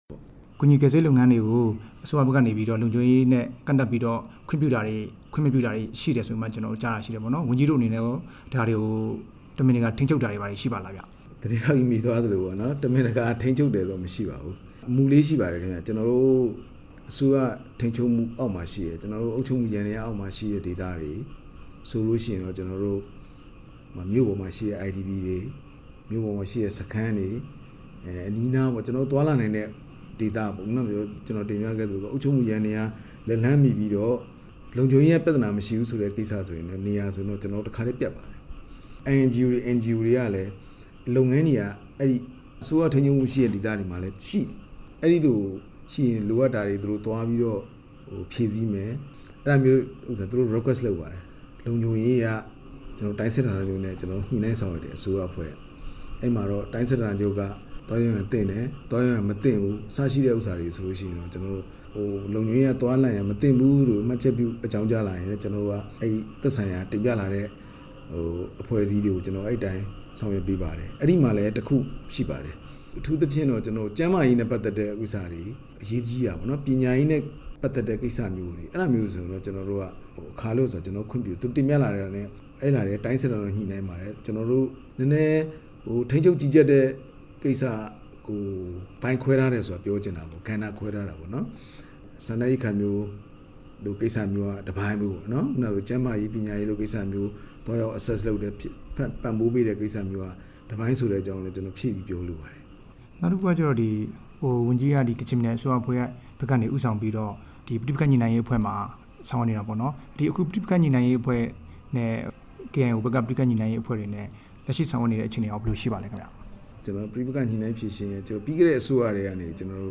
ကချင်ပြည်နယ် လုံခြုံရေးနဲ့ နယ်စပ်ရေးရာဝန်ကြီး ဗိုလ်မှူးကြီး သူရမျိုးတင် နဲ့မေးမြန်းချက်
မြစ်ကြီးနားမြို့က ပြည်နယ်အစိုးရအဖွဲ့ရုံးမှာ မနေ့က ပြုလုပ်တဲ့ သတင်းစားရှင်းလင်းပွဲအပြီး RFA နဲ့ သီးသန့်တွေ့ဆုံရာမှာ ဗိုလ်မှူးကြီး သူရမျိုးတင် က အဲဒီလိုပြောတာဖြစ်ပါတယ်။